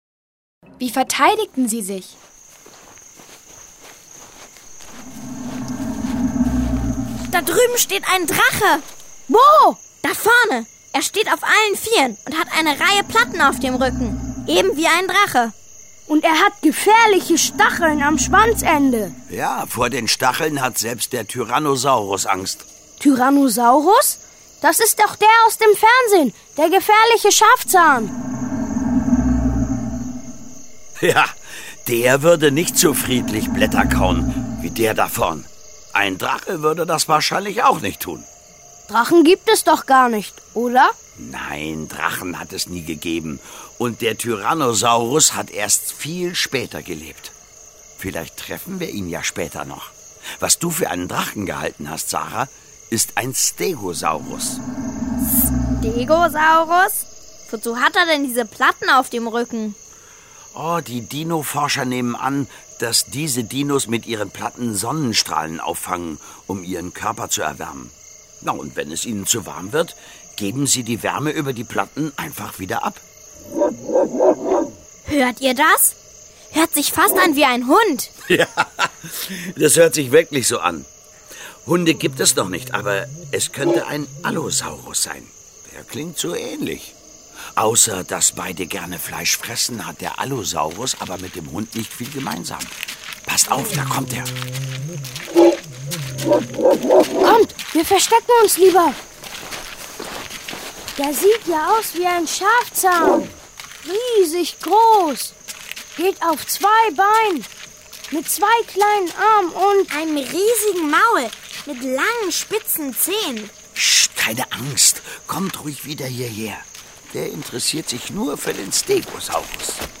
Authentische Geräusche, viel Musik und das Eröffnungslied machen das Ganze zum spannenden Hörerlebnis nach dem Motto "Ich bin ganz Ohr!"
Schlagworte Dinosaurier • Dinosaurier; Kindersachbuch/Jugendsachbuch • Dinosaurier; Kindersachbuch/Jugendsachbuch (Audio-CDs) • Hörbuch für Kinder/Jugendliche • Hörbuch für Kinder/Jugendliche (Audio-CD) • Hörbuch; Lesung für Kinder/Jugendliche • Tiere • Urzeit